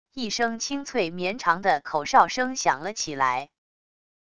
一声清脆绵长的口哨声响了起来wav音频